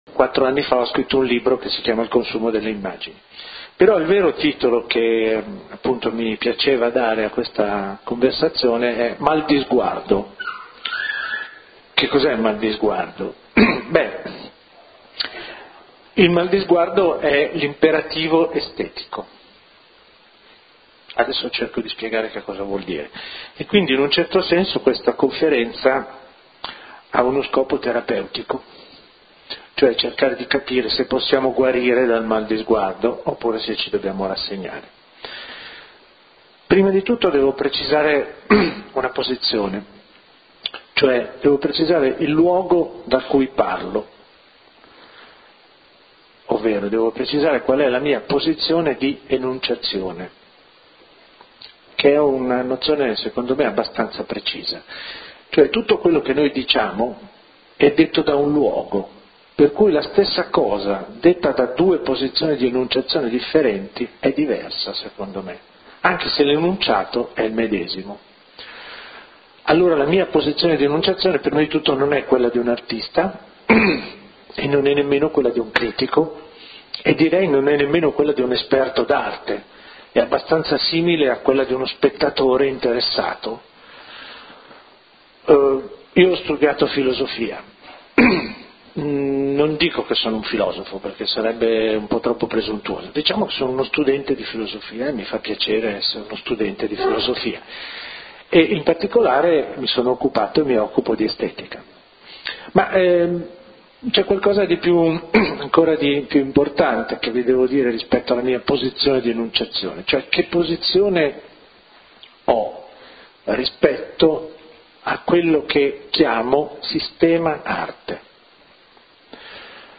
LECTURE / Il consumo delle immagini